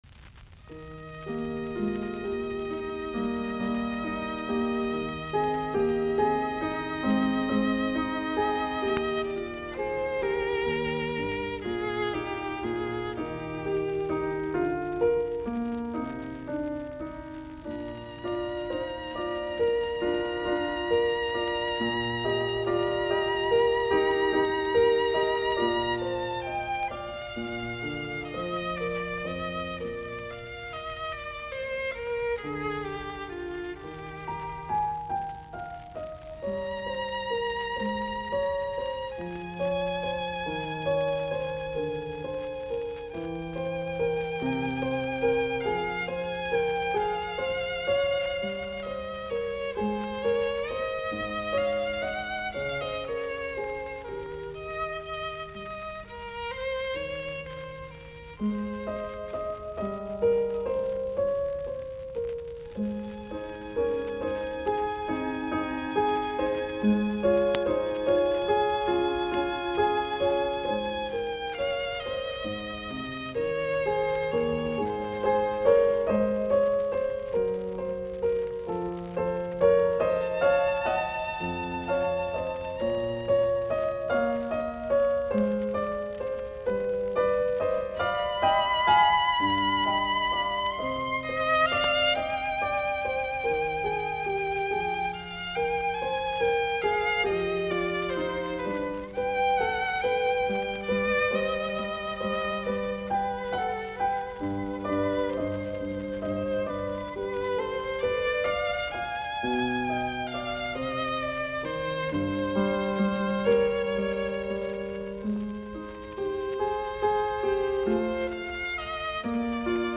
Soitinnus : Viulu, piano